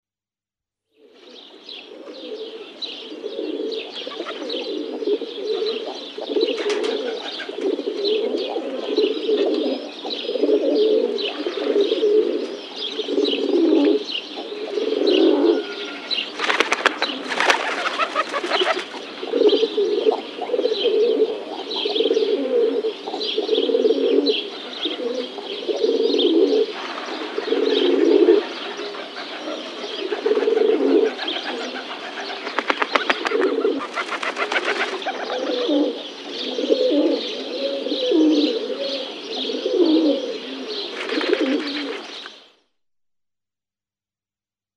Chant :
Pigeon biset
Le Pigeon biset caracoule, jabotte, roucoule. Roucoulement plaintif assez monotone très proche du pigeon domestique. Druouu-uu répété plusieurs fois.
Il consiste en un son sourd et ronronnant que les mâles émettent face aux femelles.
06RockDove.mp3